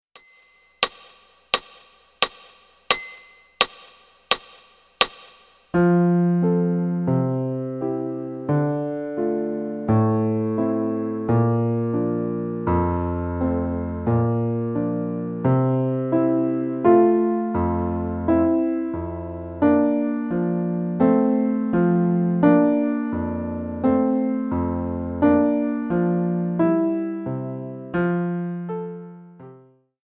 Piano/Organ Solo Piano Music Sacred
Piano